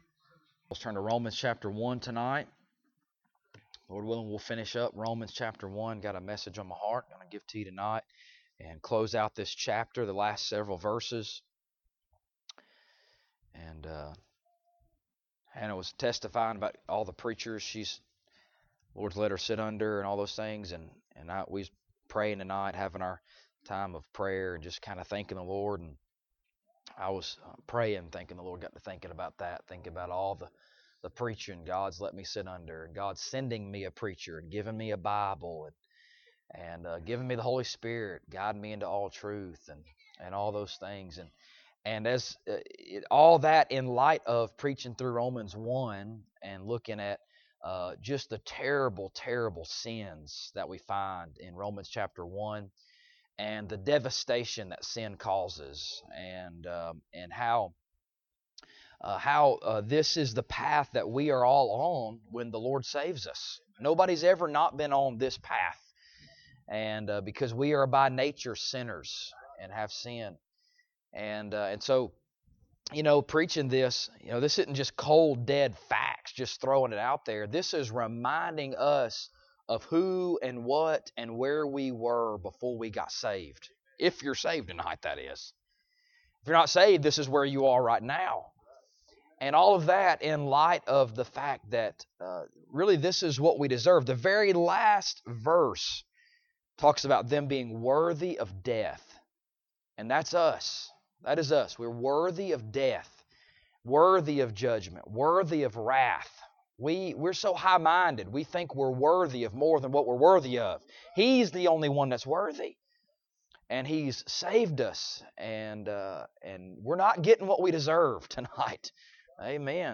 Romans Passage: Romans 1:18-32 Service Type: Sunday Evening Topics